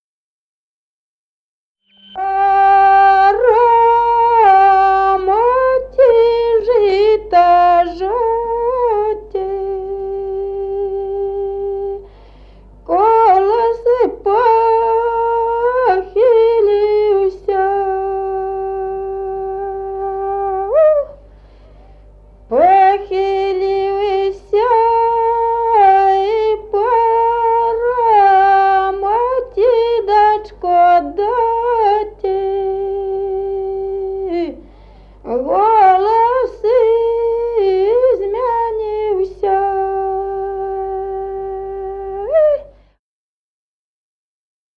Народные песни Стародубского района «Пора, мати, жито жати», зажиночная.
с. Мохоновка.